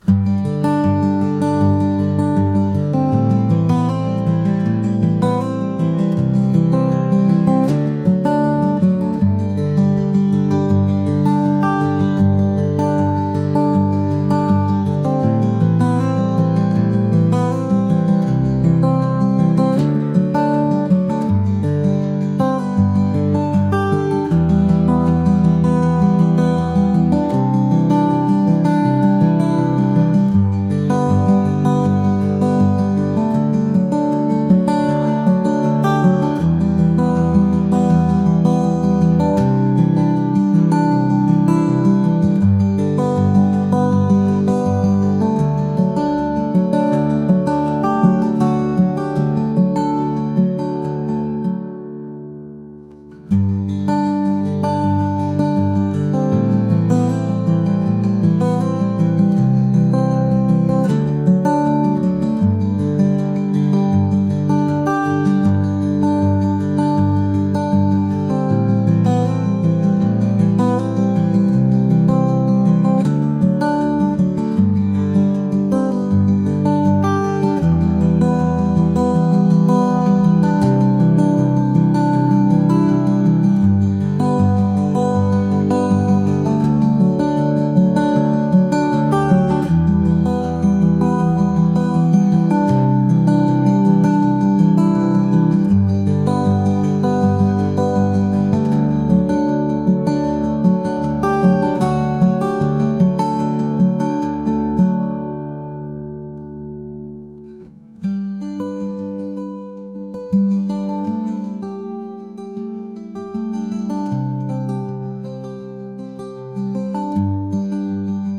acoustic | folk | soulful